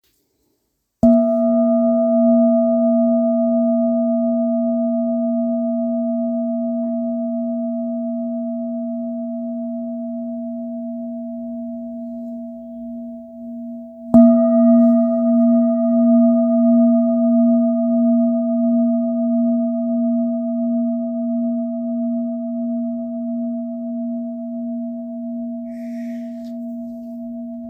Kopre Singing Bowl, Buddhist Hand Beaten, Antique Finishing
Material Seven Bronze Metal